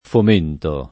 fomento [ fom % nto ]